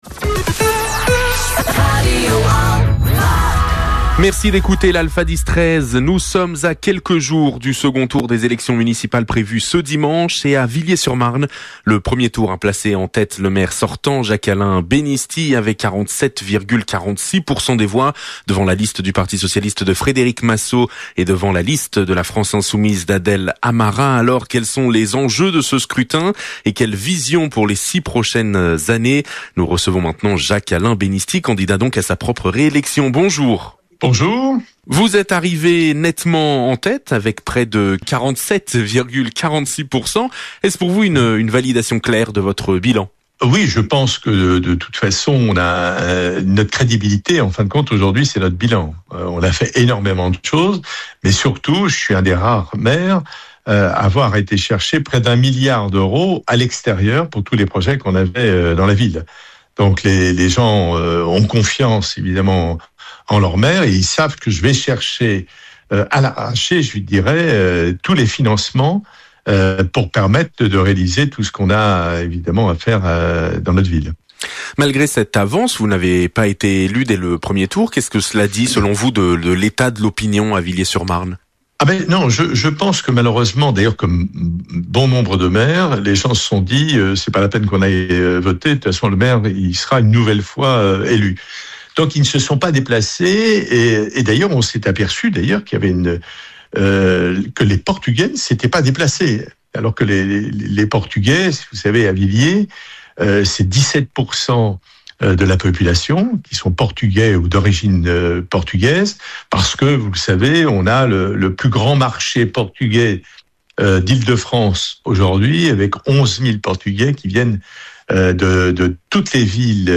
Quelles sont ses propositions ? Il répond aux questions de Radio Alfa.
Interview-Jacques-Alain-Benisti.mp3